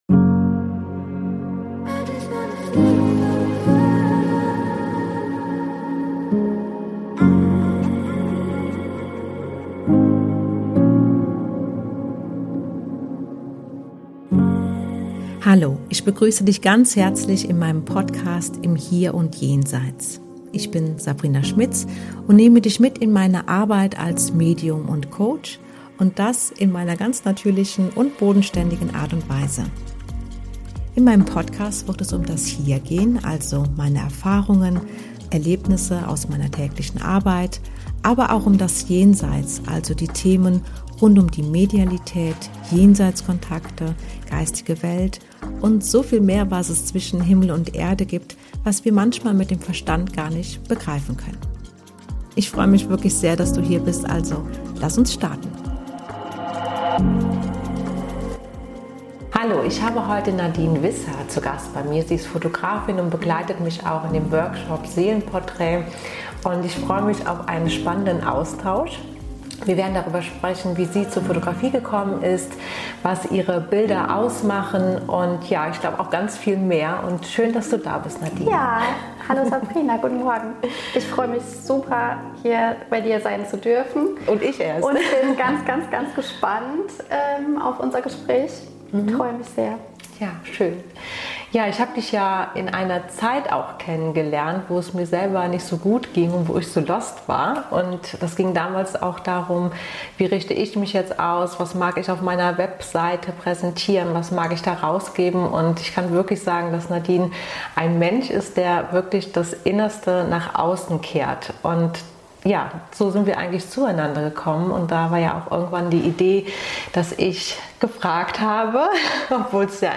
#11 Was ist eine Jenseitskontakt-Demonstration? Mit Interviewgast